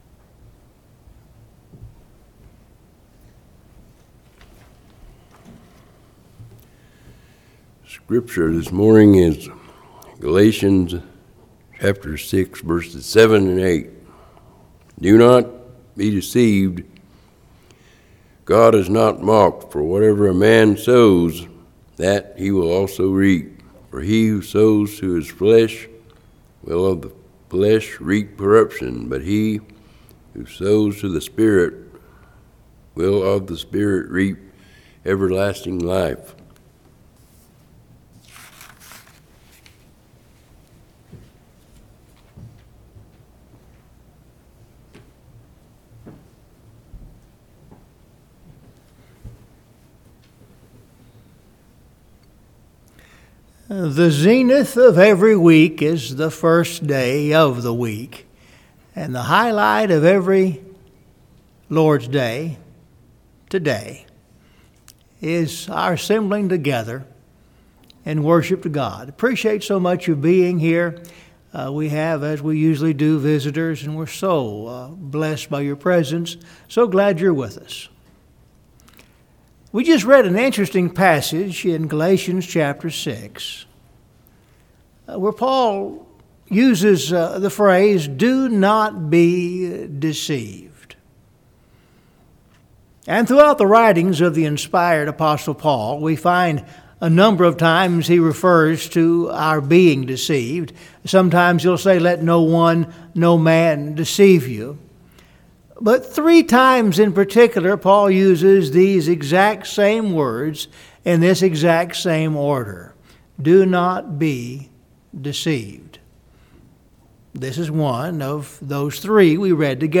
Scripture Reading